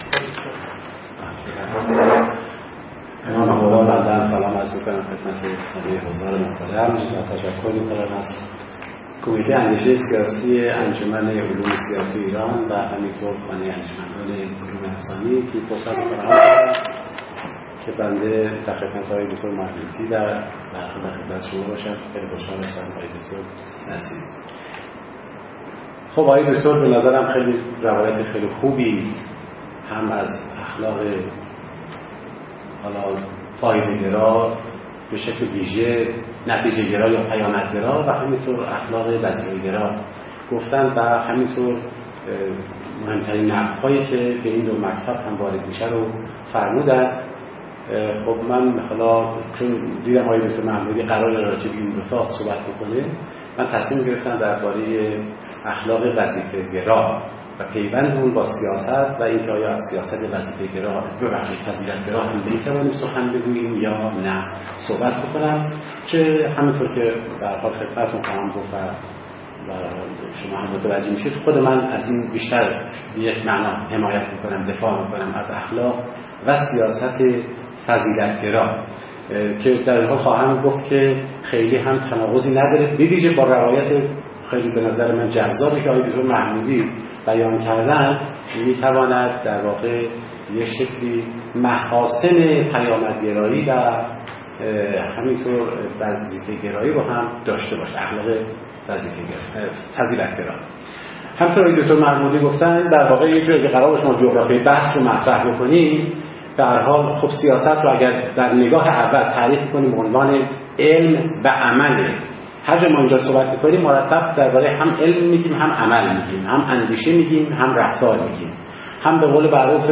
این نشست به همت انجمن علوم سیاسی و خانه اندیشمندان علوم انسانی چهارشنبه ۱۸ شهریور ماه ۹۴ در خانه اندیشمندان علوم انسانی برگزار شد.